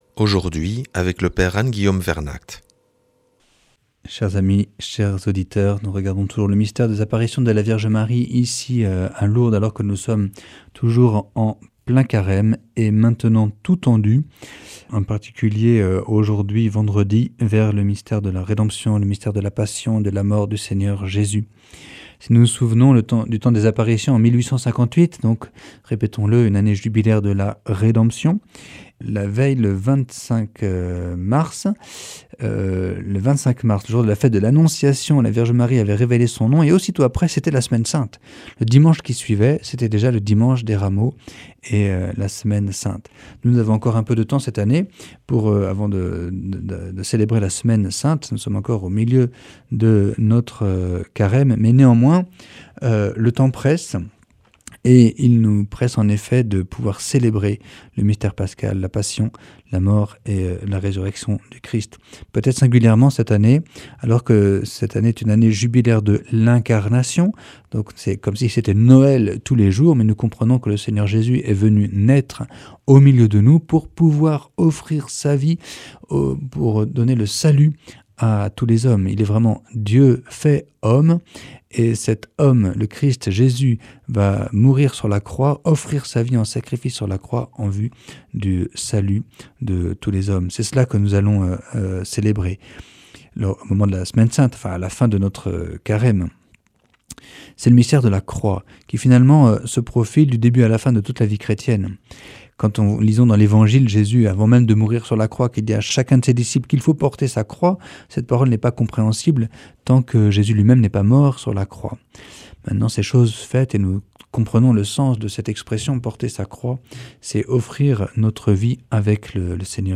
Enseignement Marial du 21 mars